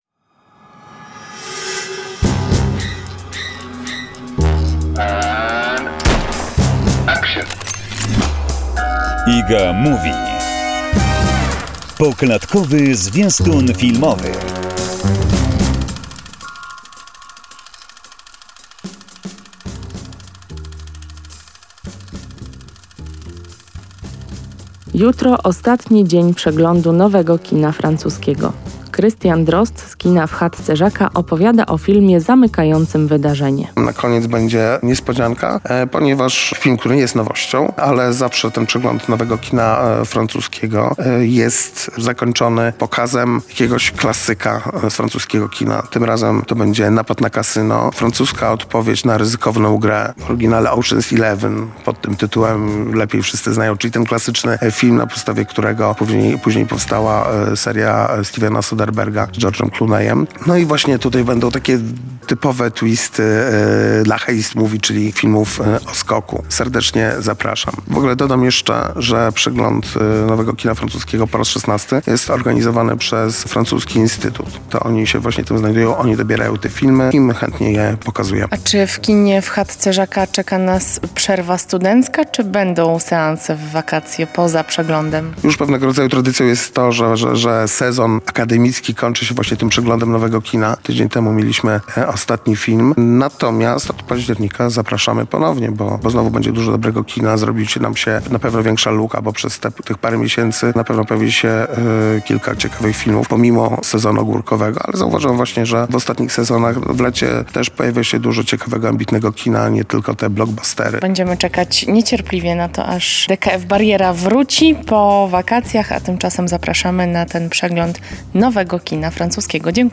rozmowy